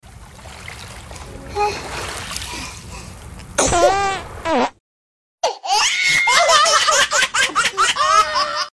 Cute Baby “sneeze” With Orange Sound Effects Free Download